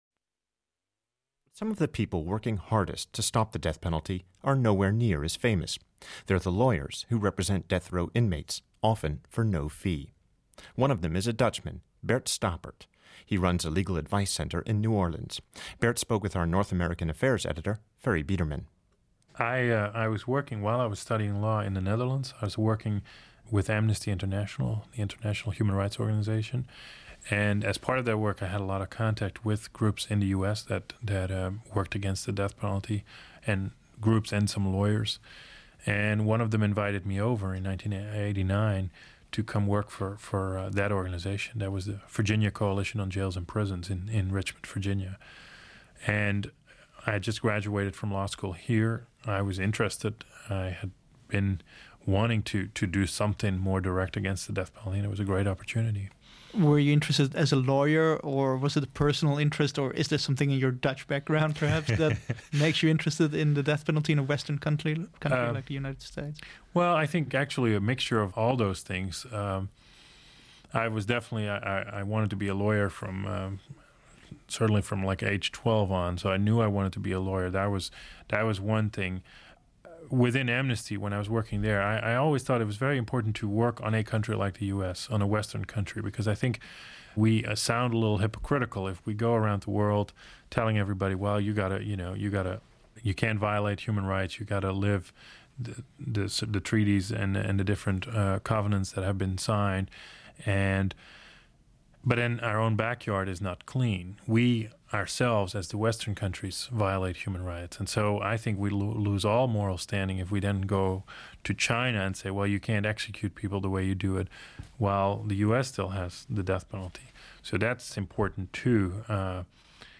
Interviewer